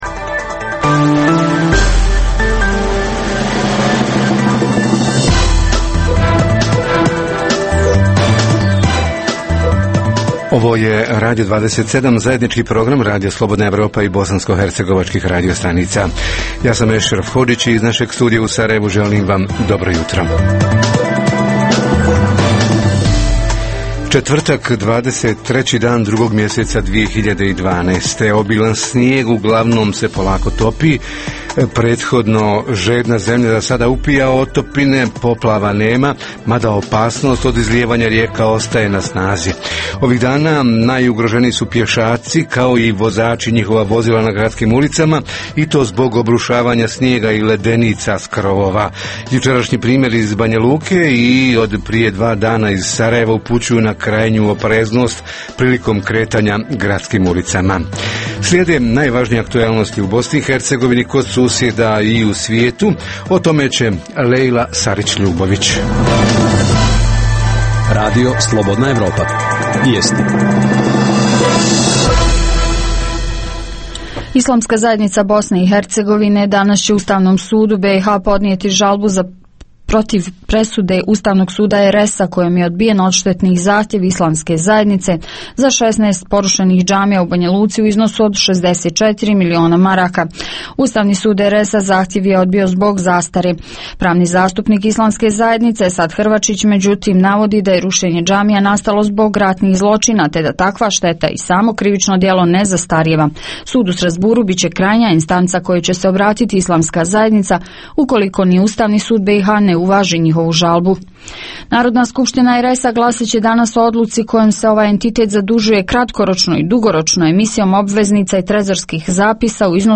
Tema jutra: Obilan snijeg i privreda – koje su i kolike posljedice, može li se, kada i kako propušteno nadoknaditi? - Reporteri iz cijele BiH javljaju o najaktuelnijim događajima u njihovim sredinama.
Redovni sadržaji jutarnjeg programa za BiH su i vijesti i muzika.